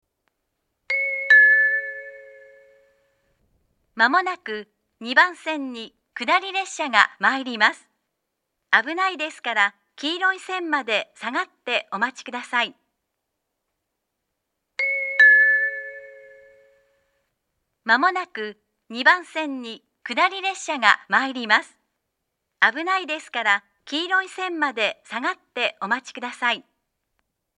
接近放送は首都圏でもよく聞ける東海道型の放送です。
２番線接近放送
shirakawa-2bannsenn-sekkinn1.mp3